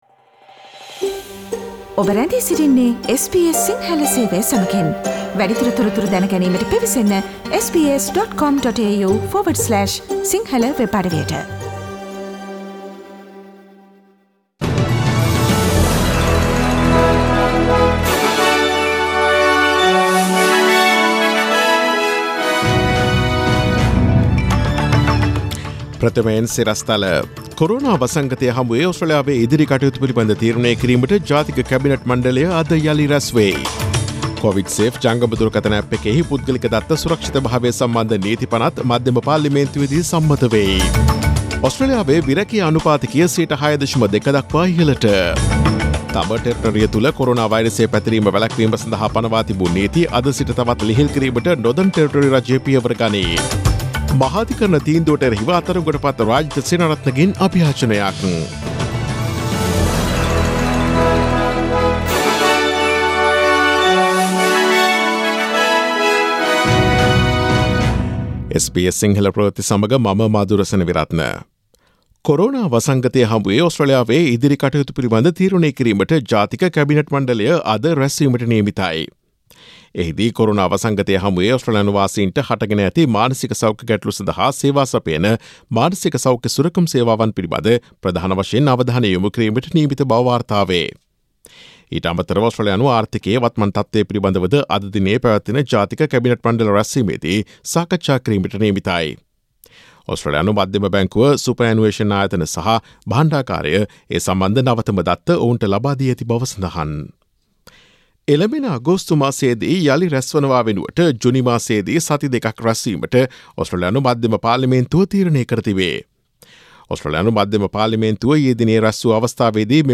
Daily News bulletin of SBS Sinhala Service: Friday 15 May 2020
Today’s news bulletin of SBS Sinhala Radio – Friday 15 May 2020 Listen to SBS Sinhala Radio on Monday, Tuesday, Thursday and Friday between 11 am to 12 noon